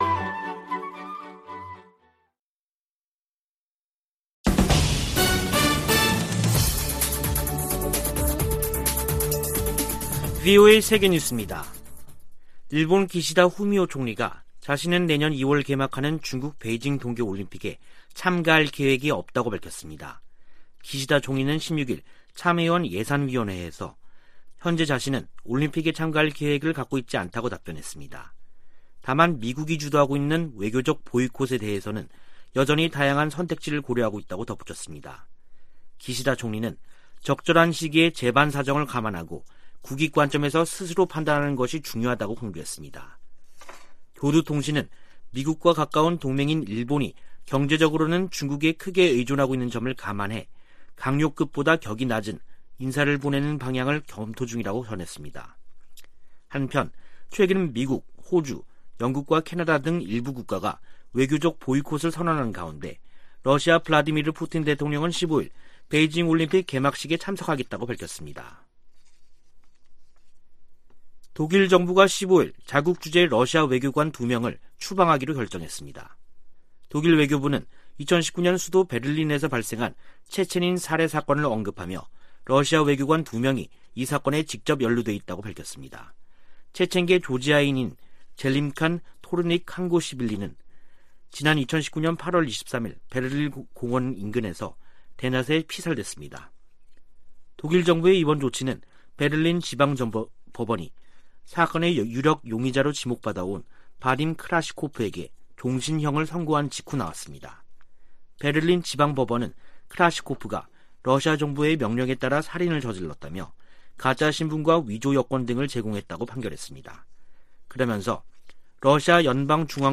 VOA 한국어 간판 뉴스 프로그램 '뉴스 투데이', 2021년 12월 16일 2부 방송입니다. 유엔 안보리가 올해도 북한 인권 관련 비공개 회의를 개최한 가운데, 일부 이사국들이 북한 정권의 인권유린 실태를 비판했습니다. 미 상원이 국방수권법안을 가결함에 조 바이든 대통령 서명만 거치면 효력을 갖습니다. 신종 코로나바이러스 감염증 사태가 북한에 두고 온 가족들에게 생활비를 보내 온 한국 내 탈북민들의 부담을 높이고 있습니다.